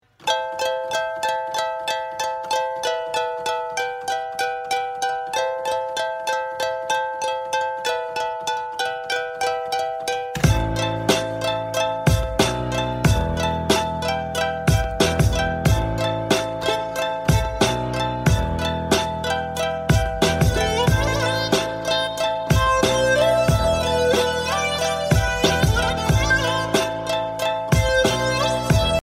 Categories Classical Ringtones